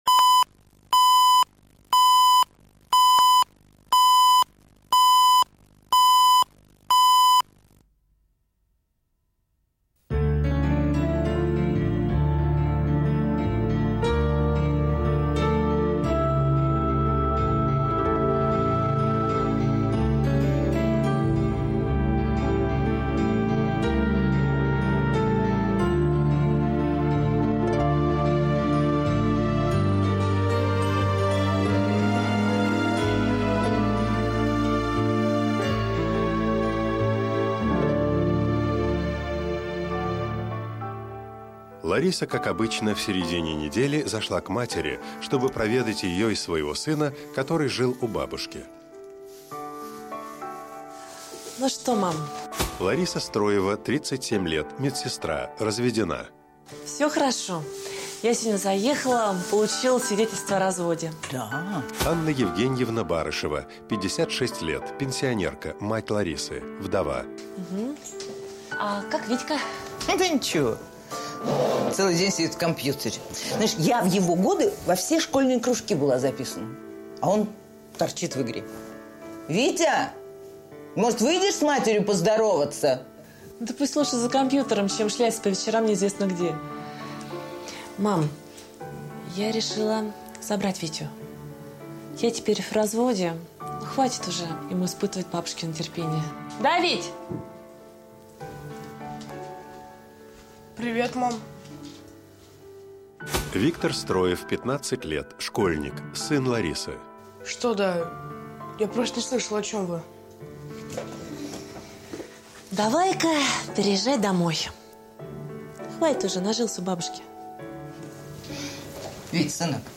Аудиокнига Потерянный сын | Библиотека аудиокниг
Прослушать и бесплатно скачать фрагмент аудиокниги